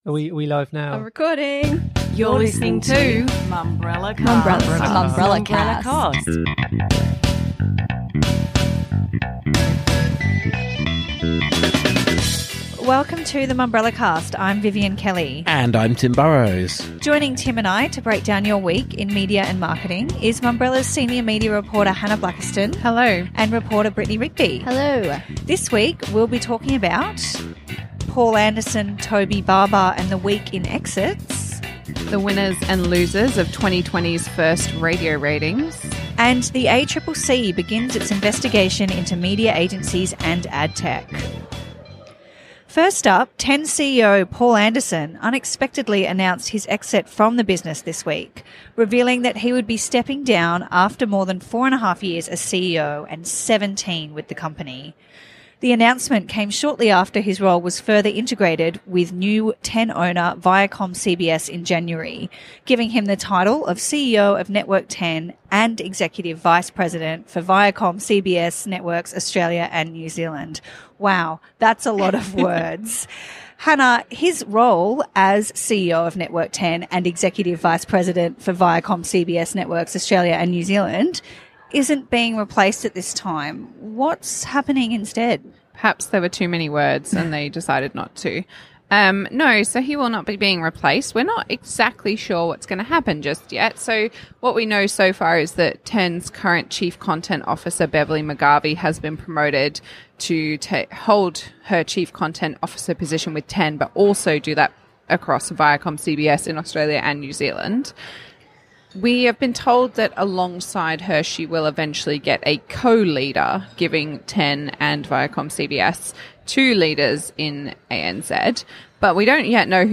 This week’s Mumbrellacast comes to you from the lunch hour at Mumbrella’s Travel Marketing Summit, when the team sat down to talk through the week of media and marketing.